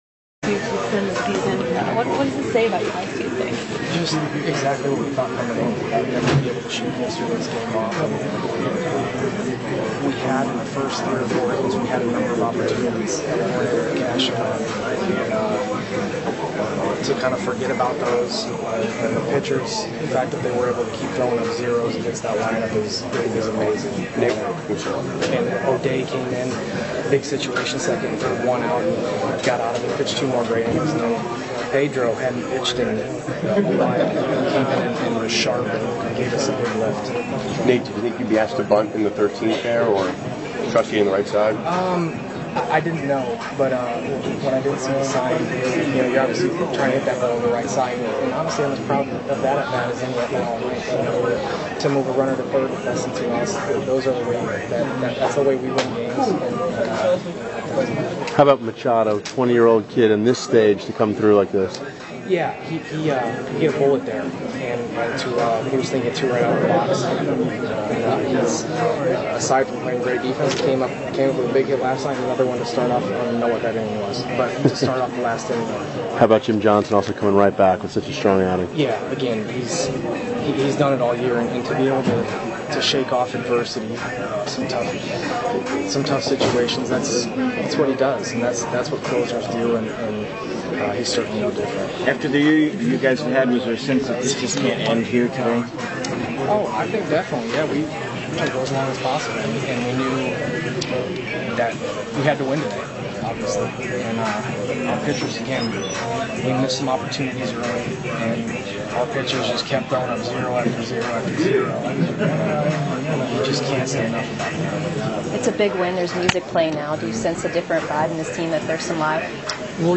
Nate McLouth Clubhouse Audio